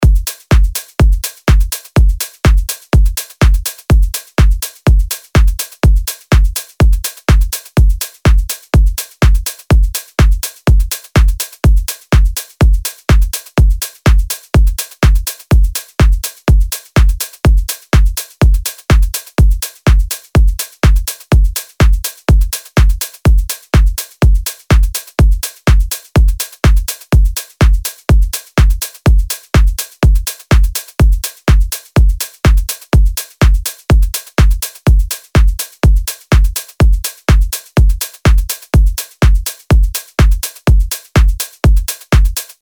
LP 208 – DRUM LOOP – EDM – 124BPM